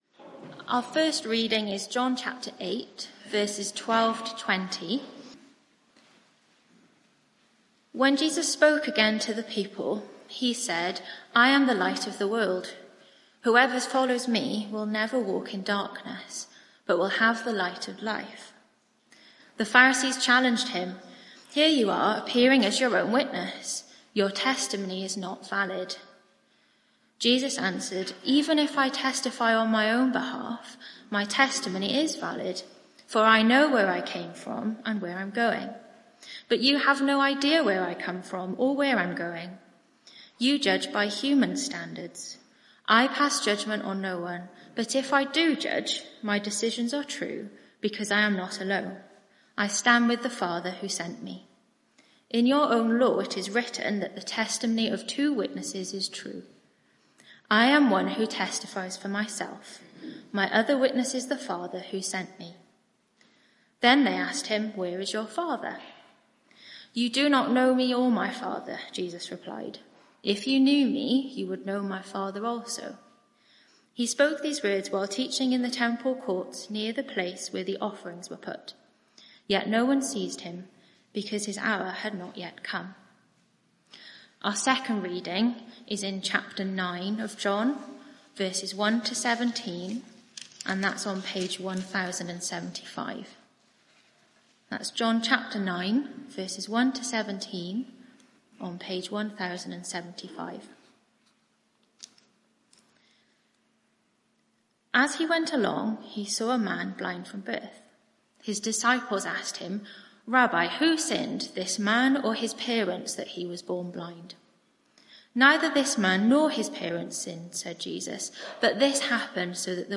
Media for 11am Service on Sun 04th Aug 2024 11:00 Speaker
Theme: I am the light of the world Sermon (audio)